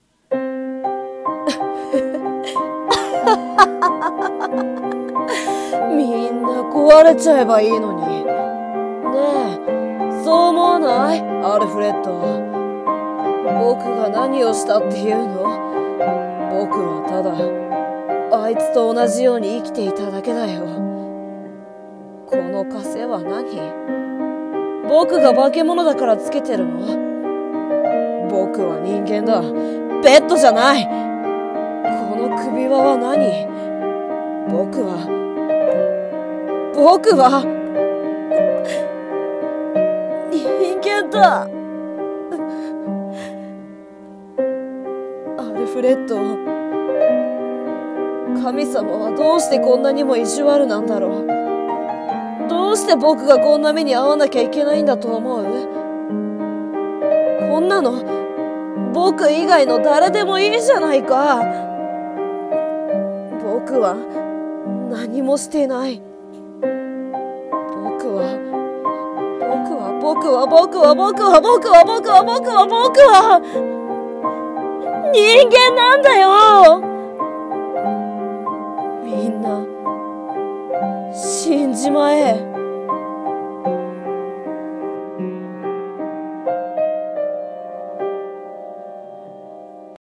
【声劇】化物の少年